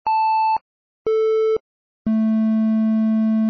三角波・単音 (mp3/11kb)
三角波は、直線で表せる波形の中では、もっとも正弦波に近い「丸い」音の出るものの一つです。
triangle.mp3